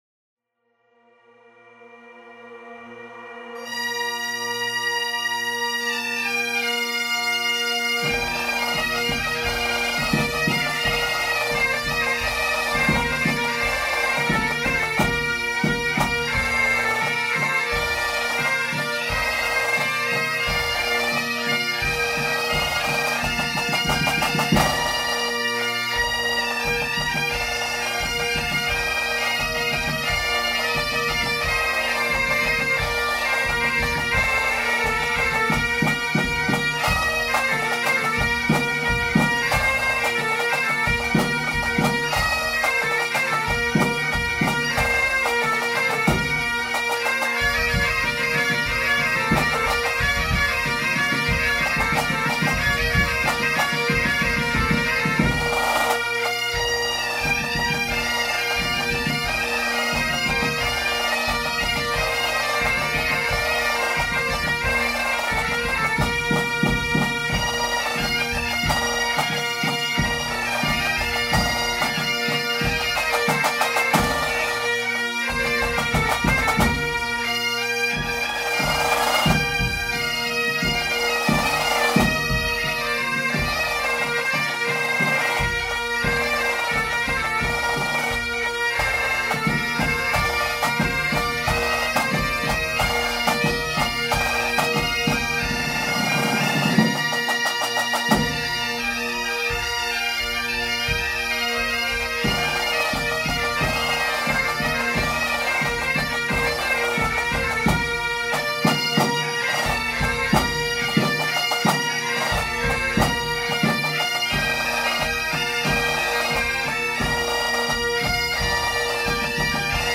Galician music, bagpipe, gaita, percussion instruments, musical genres, Celticity